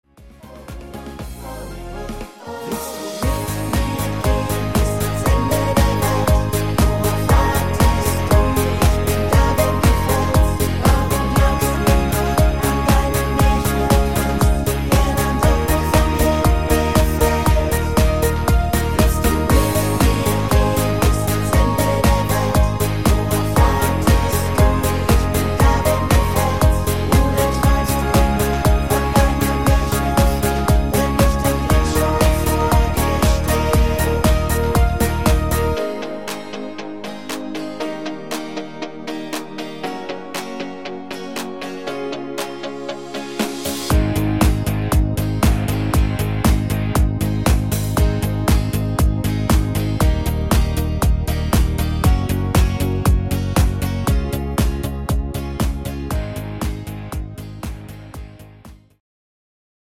Ein super gutes neues Duett